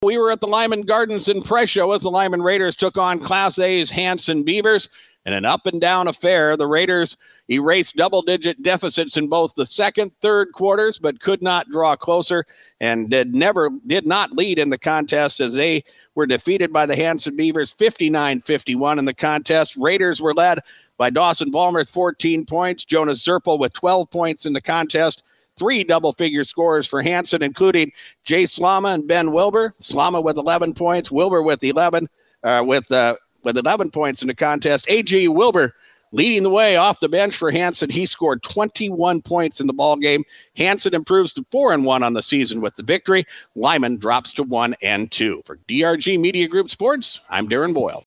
Recap of Lyman vs. Hanson boys basketball game from Thursday night